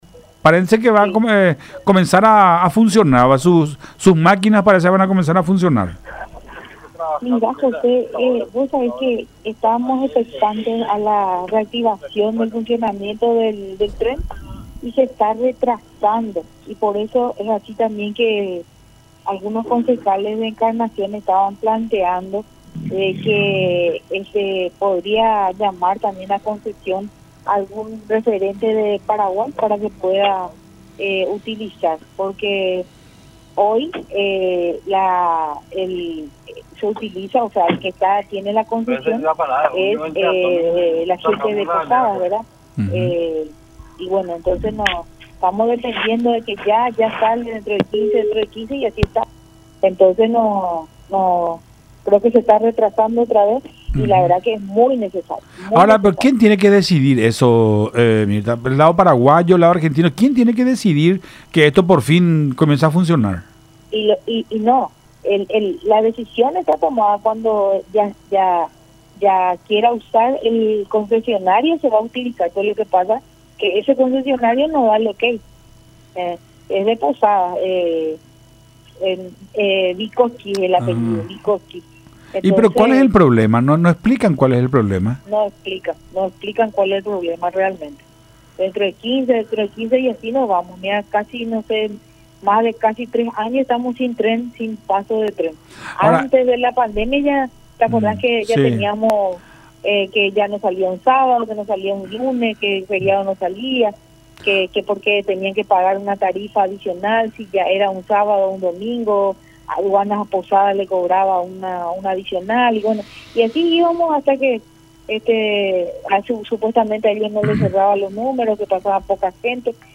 en diálogo con Nuestra Mañana a través de Unión TV y radio La Unión.